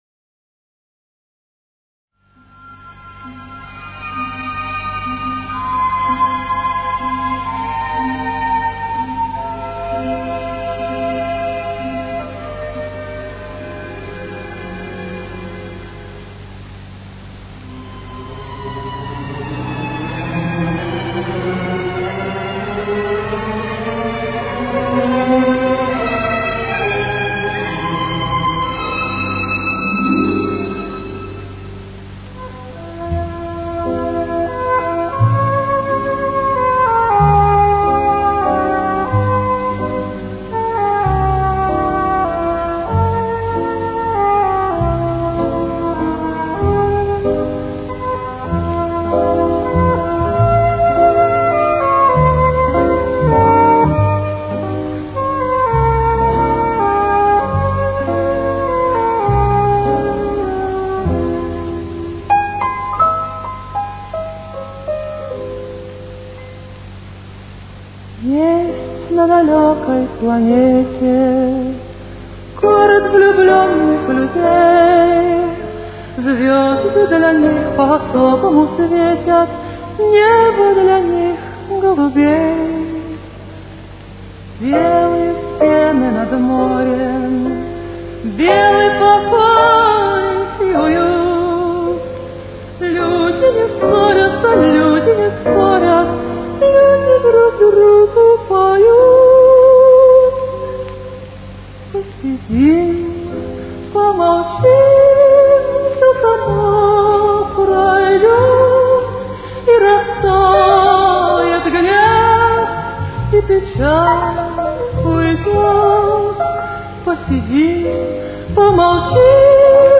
Темп: 102.